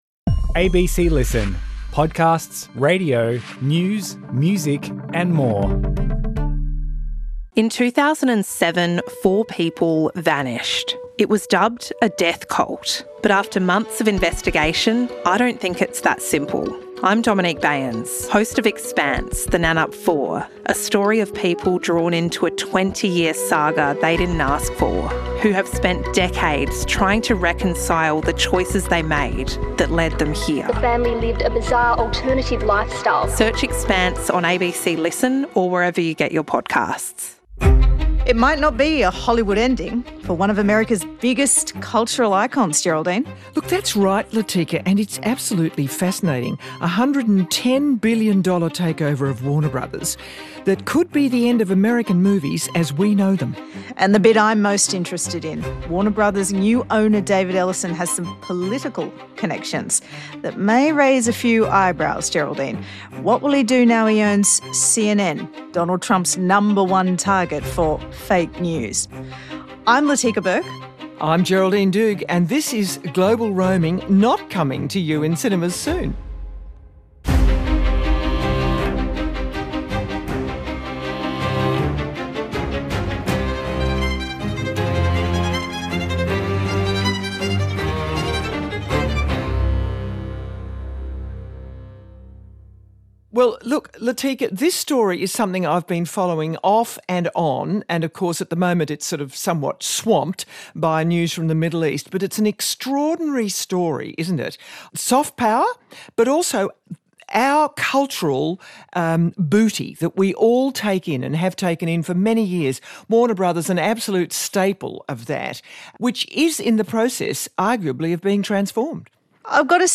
Geraldine Doogue and Latika Bourke are joined by legendary entertainment reporter Kim Masters, who first broke the news of a potential deal last year, to talk about David Ellison's journey from aspiring actor to media mogul; his controversial instalment of Bari Weiss at CBS; and what his political connections say about the shrinking free press.
Guest: Kim Masters, co-founder and writer for Puck